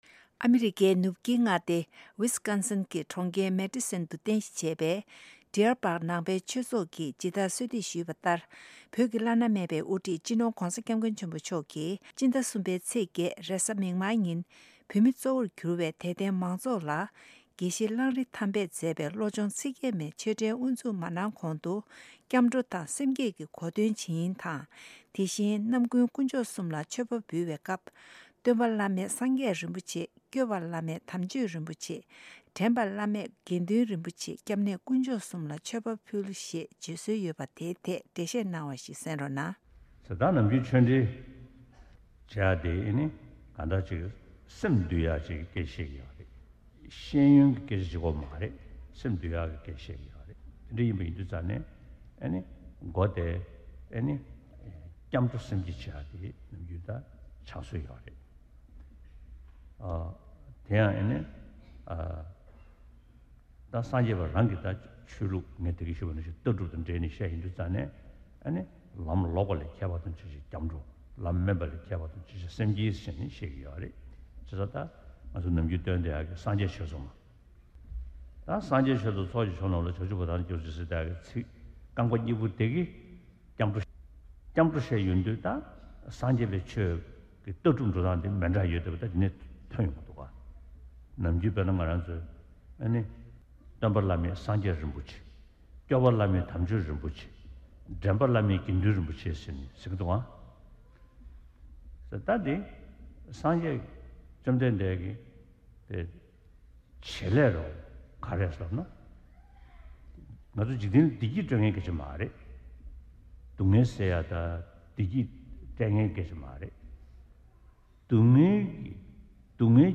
སྤྱི་ནོར་༧གོང་ས་༧སྐྱབས་མགོན་ཆེན་པོ་མཆོག་གིས་ཉེ་དུས་ཨ་མི་རི་ཀའི་མི་ནི་སོ་ཊའི་མངའ་སྡེའི་ནང་གནས་འཁོད་ས་གནས་བོད་མི་གཙོ་བོར་གྱུར་བའི་དད་ལྡན་མང་ཚོགས་ལ་ཆོས་འབྲེལ་དང་ བླང་འདོར་གྱི་བཀའ་སློབ་གནང་བའི་དུམ་བུ་གཉིས་པ་དེ་གསན་རོགས་གནང་།